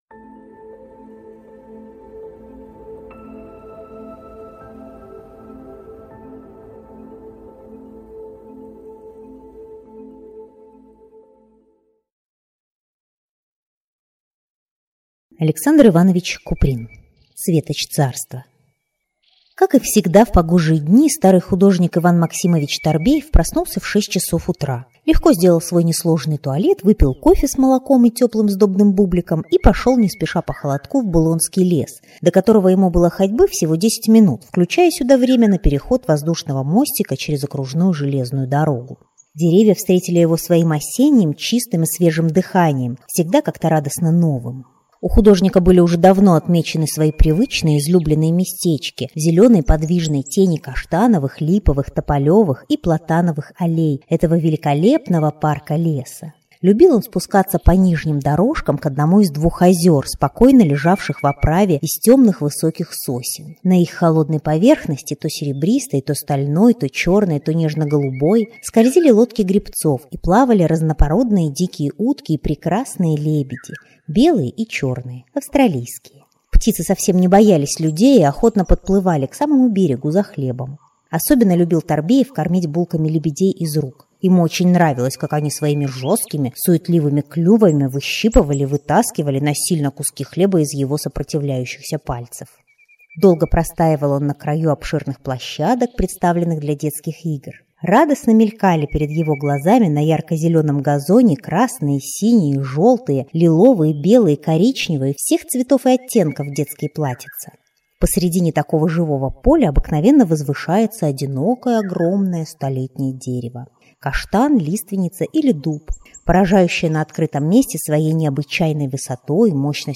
Аудиокнига Светоч царства | Библиотека аудиокниг